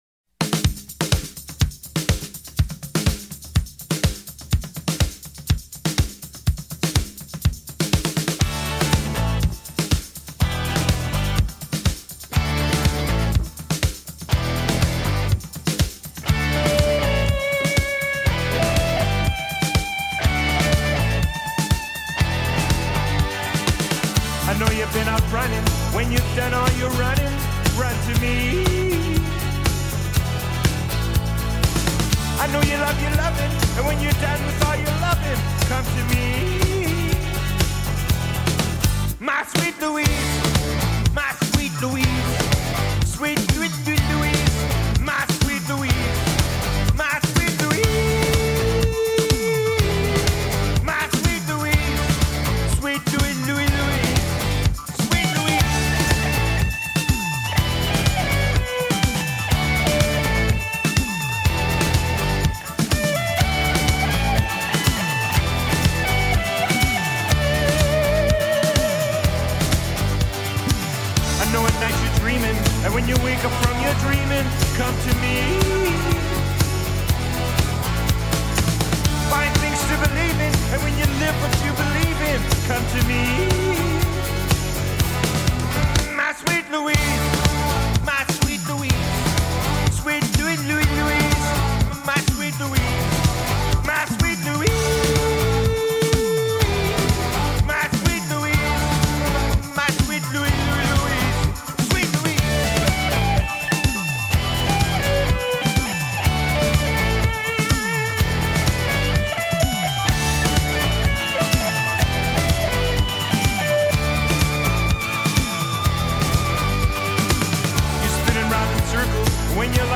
stuttering vocal style